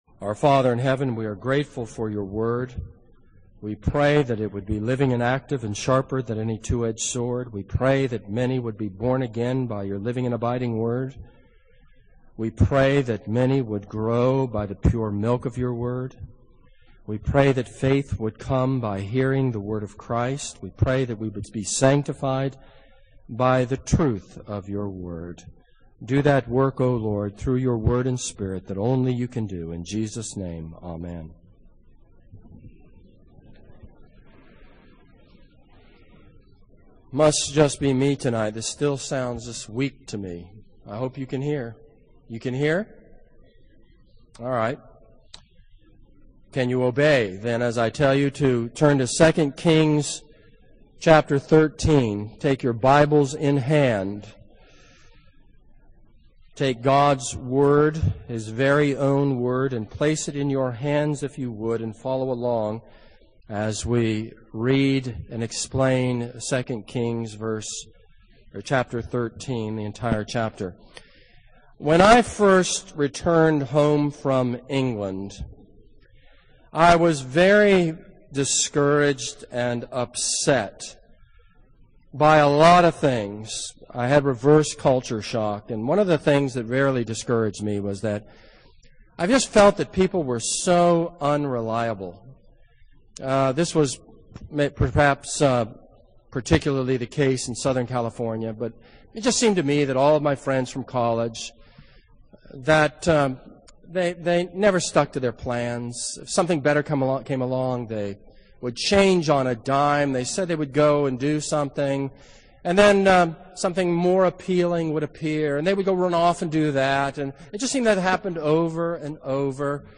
This is a sermon on 2 Kings 13.